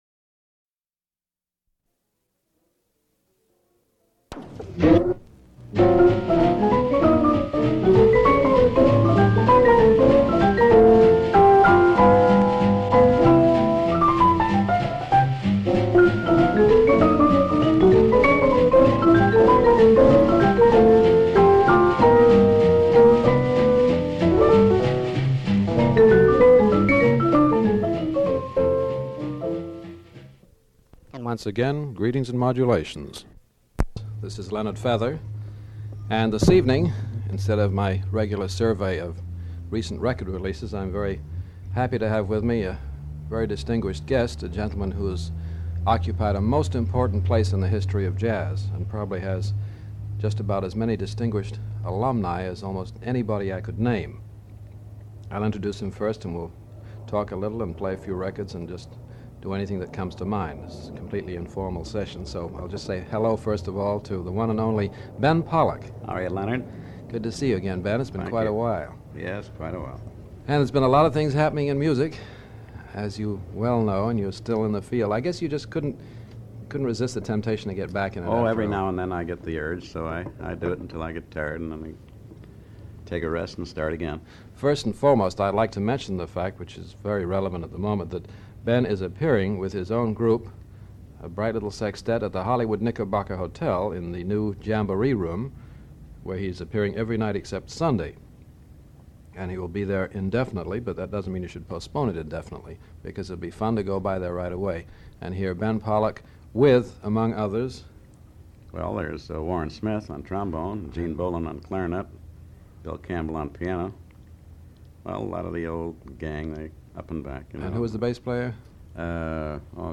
Ben Pollack Interview
Item from Leonard Feather Collection: Leonard Feather interviews Ben Pollack about new music and Pollack's biography. Ben Pollack was an American drummer.